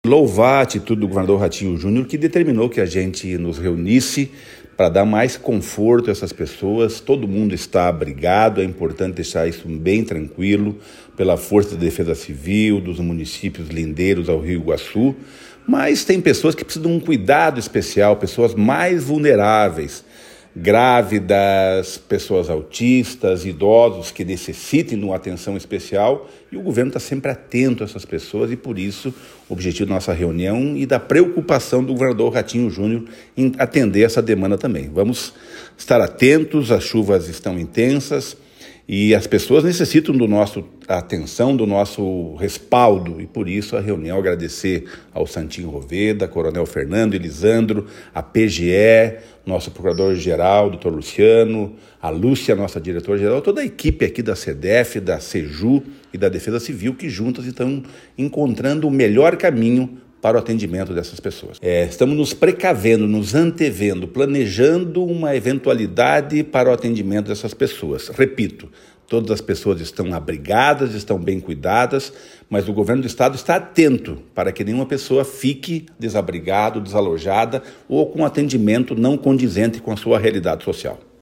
Sonora do secretário do Desenvolvimento Social e Família, Rogério Carboni, sobre o cuidado com os desabrigados das chuvas